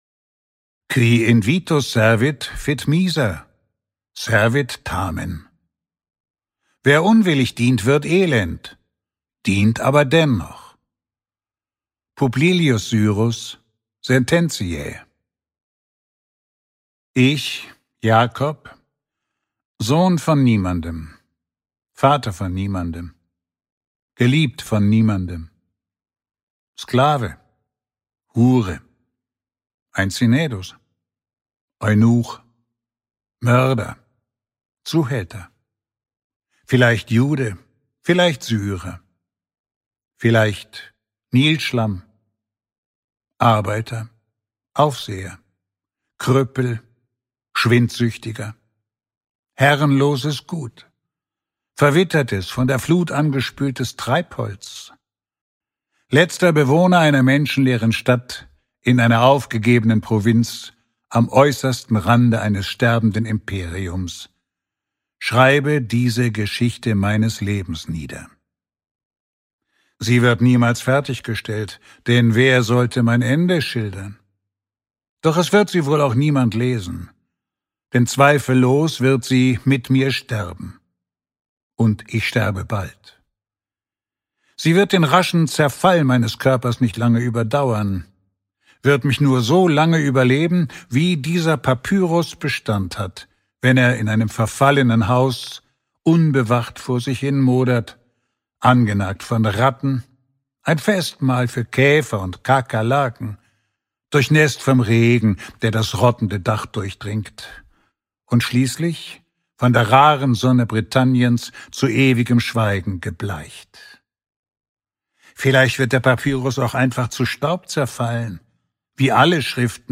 Ungekürzte Lesung mit Walter Kreye (2 mp3-CDs)
Walter Kreye (Sprecher)